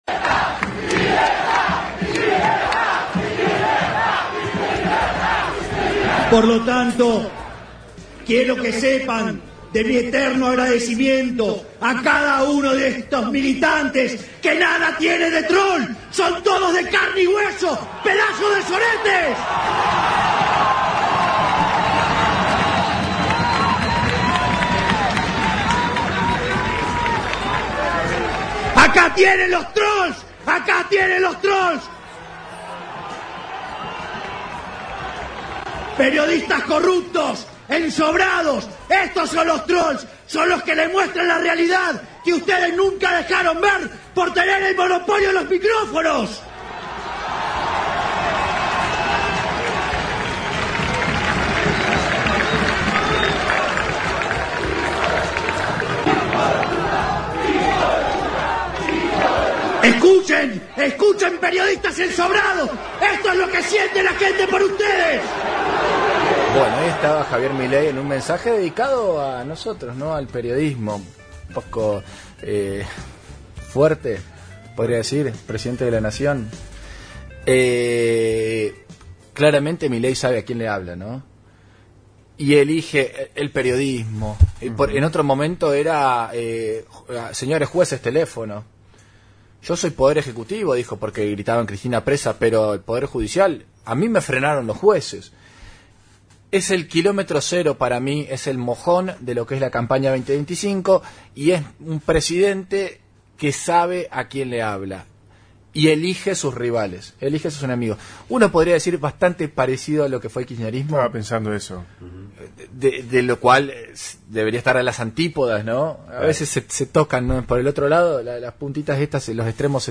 DEMOLER EL ESTADO, EMPIEZA POR LOS JUBILADOS Y LA UNIVERSIDAD (Un diálogo por radio)